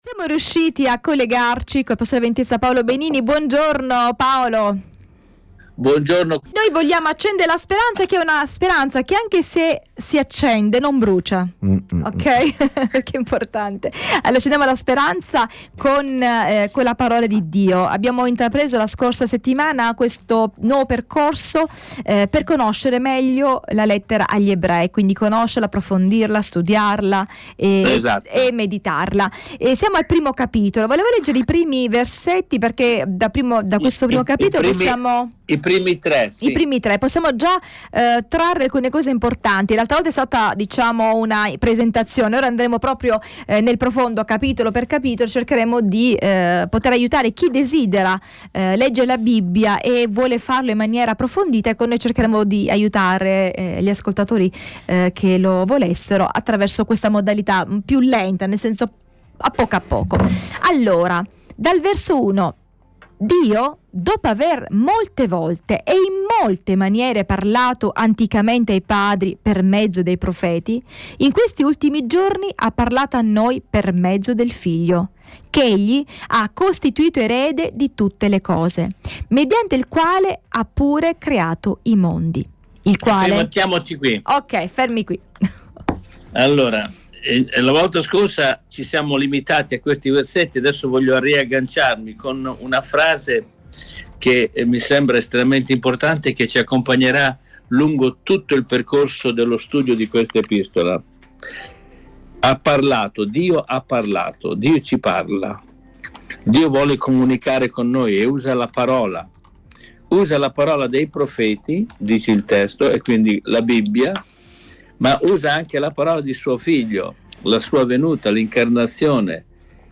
Il primo capitolo dell’epistola punta molto sui riferimenti dell’Antico Testamento per condurre il lettore ebreo alla conoscenza di chi era veramente Gesù di Nazareth. Segui il dialogo